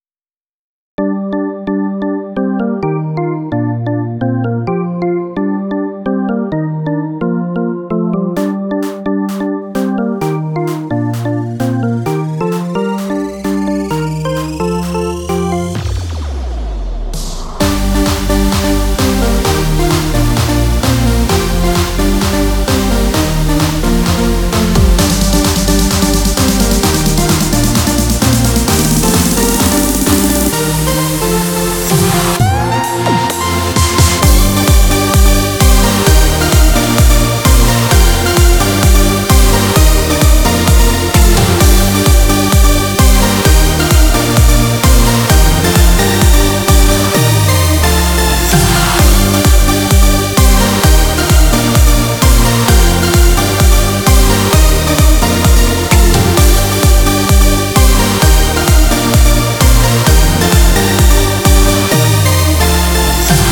אשמח לתגובות והערות על המקצבים
שמעתי --ממש יפה וסאונד מצוין